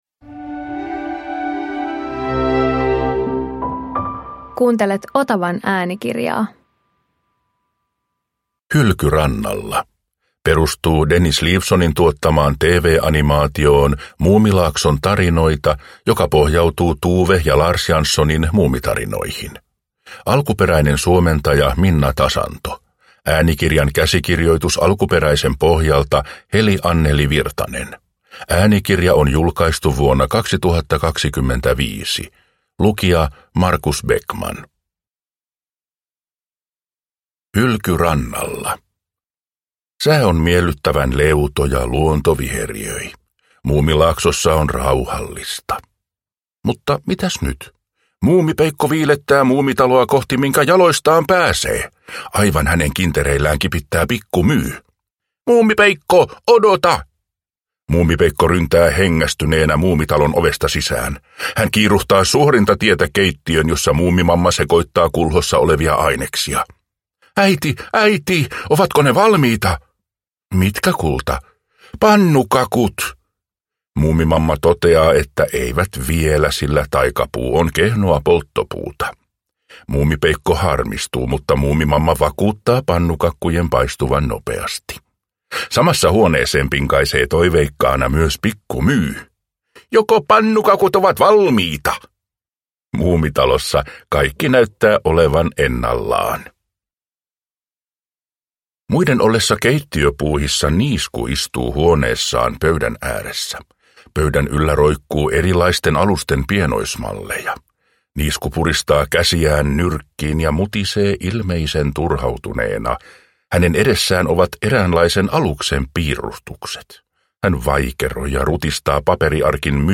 Muumi - Hylky rannalla – Ljudbok